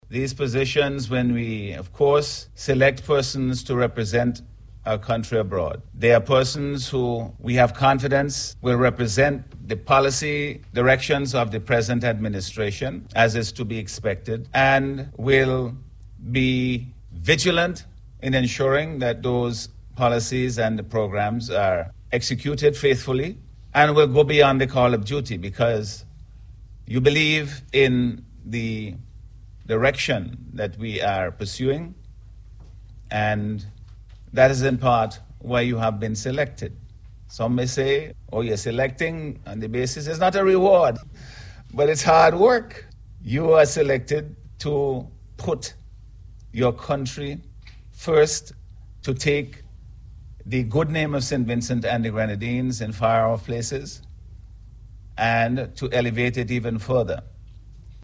Delivering remarks at the opening ceremony, Prime Minister Dr. The Hon, Godwin Friday expressed confidence in the Diplomats’ ability to function in their new roles.